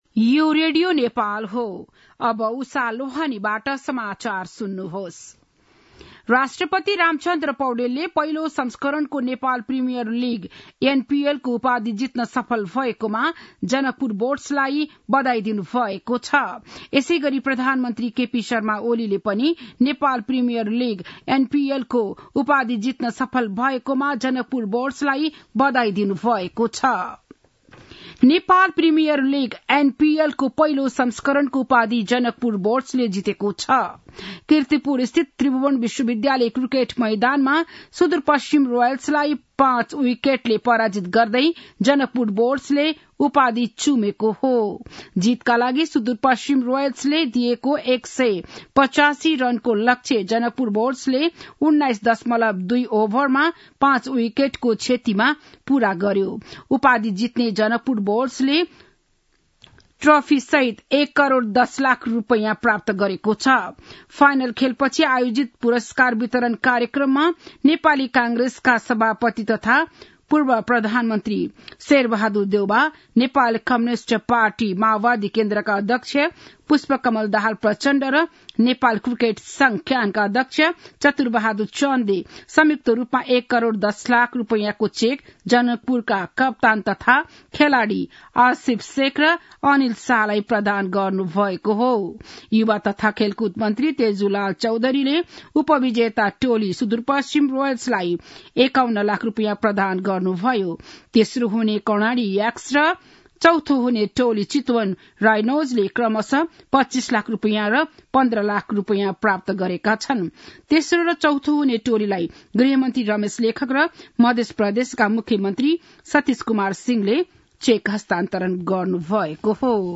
बिहान ११ बजेको नेपाली समाचार : ८ पुष , २०८१
11-am-nepali-news-1-17.mp3